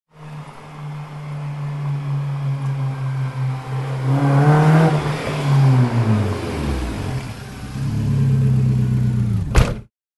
Звуки старта гонки
Скоростной автомобиль вернулся после заезда